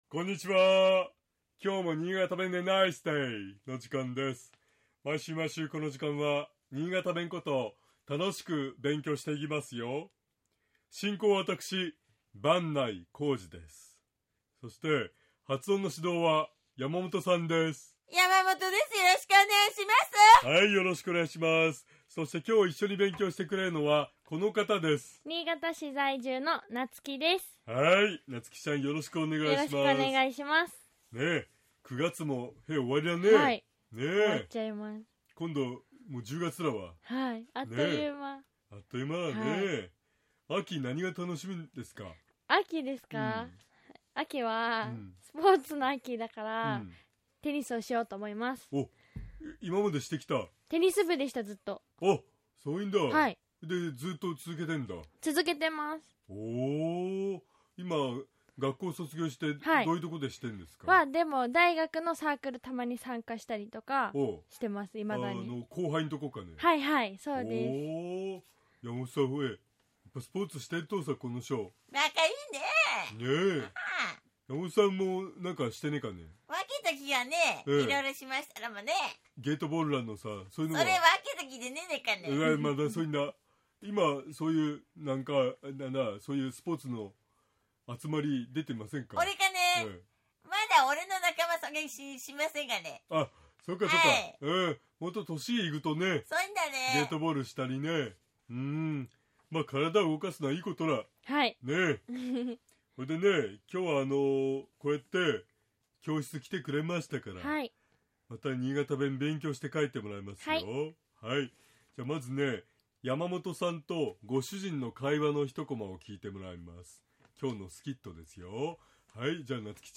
「びしょびしょです」と言う場合、新潟弁では「びっちょびちょら」と発音します。